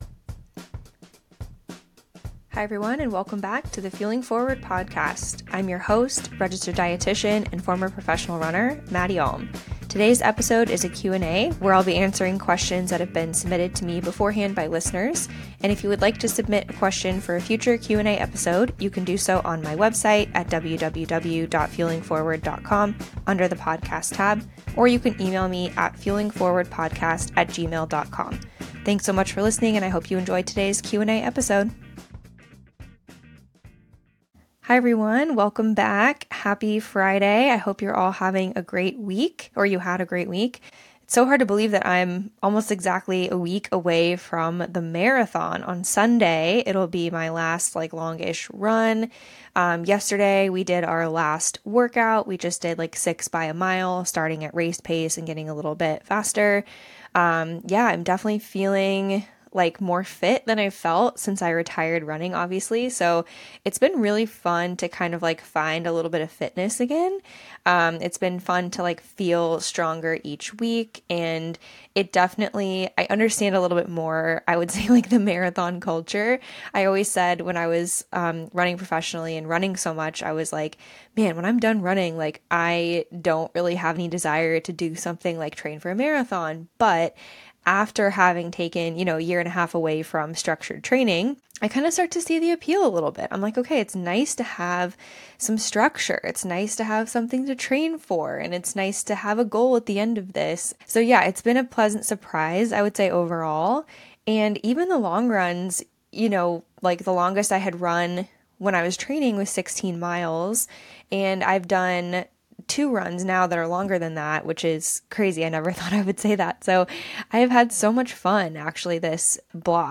Today's episode is a Q&A where I answer questions submitted by listeners. It's also my last Q&A episode before I run the New York City Marathon, so I talk a little about my training block and race day goals.